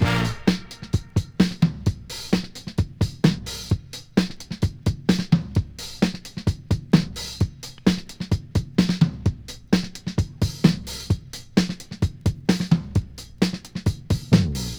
• 130 Bpm Drum Loop Sample A# Key.wav
Free breakbeat sample - kick tuned to the A# note. Loudest frequency: 720Hz
130-bpm-drum-loop-sample-a-sharp-key-Ff2.wav